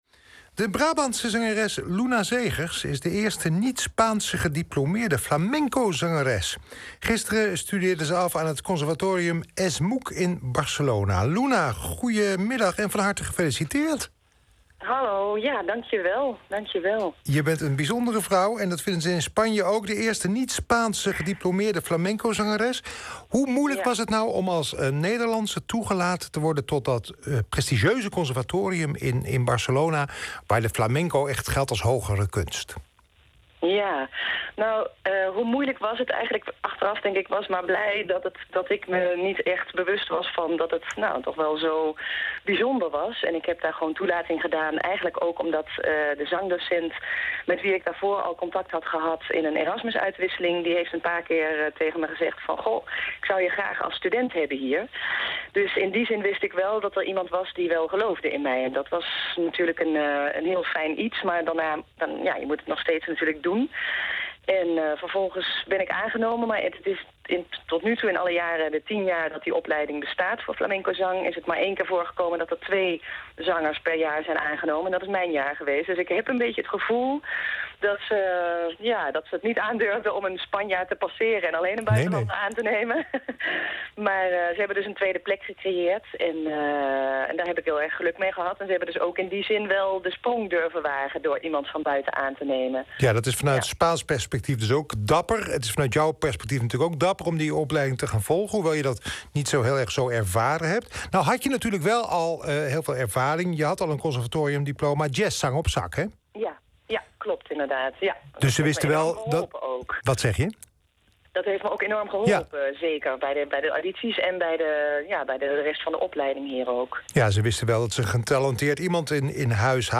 Entrevista radio